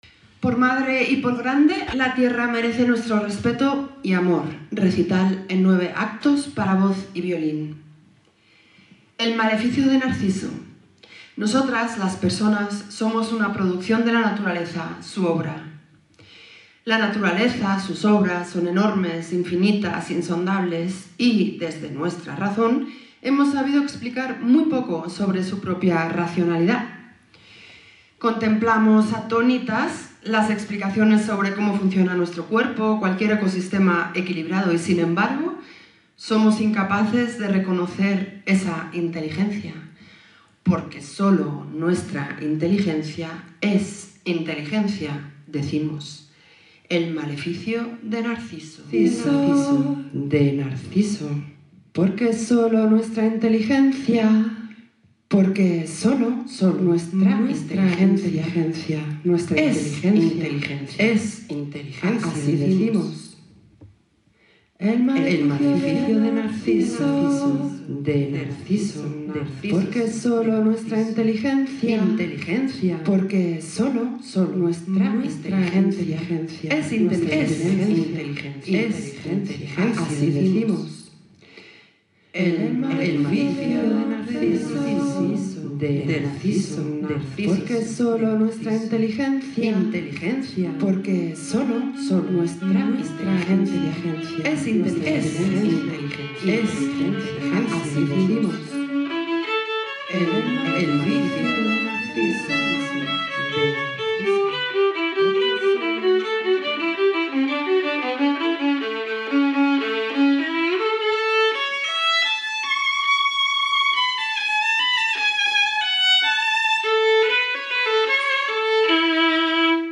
Recital para voz y violín - miSelva
Recital para voz y Violín en miMonte#1 Biblioteca municipal Jorge Martínez Reverte.